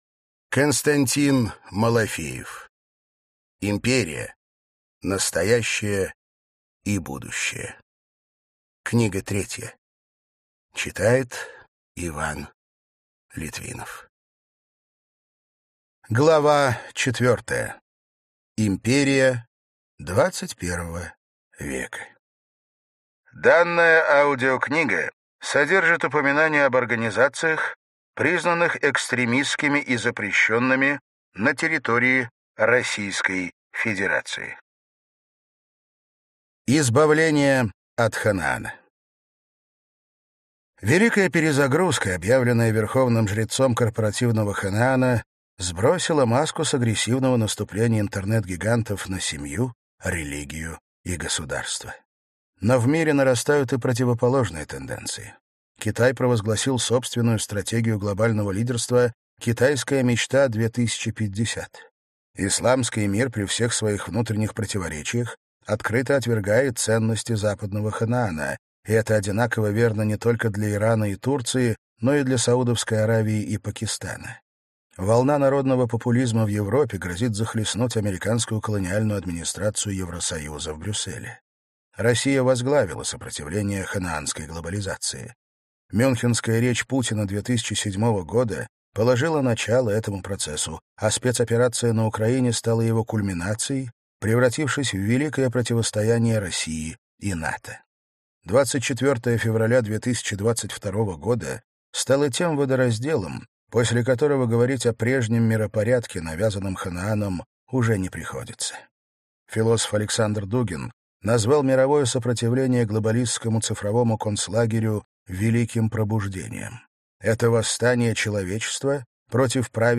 Аудиокнига Империя. Настоящее и будущее. Книга 3. Часть 4 | Библиотека аудиокниг